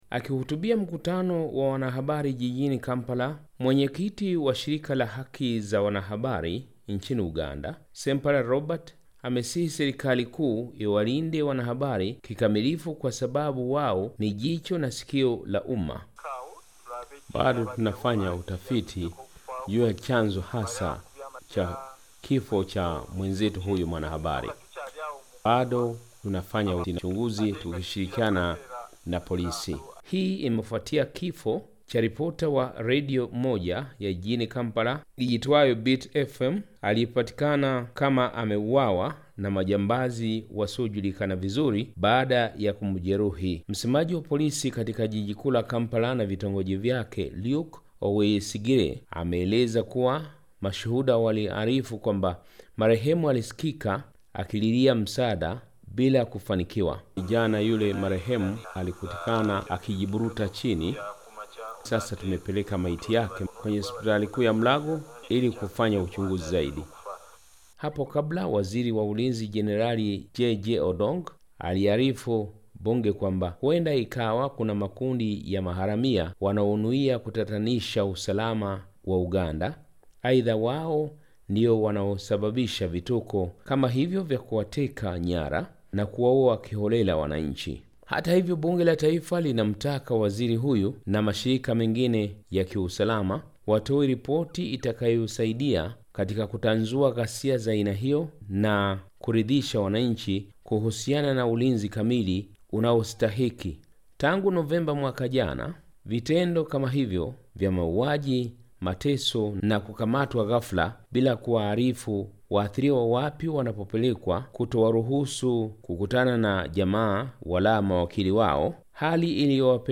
Habari / Afrika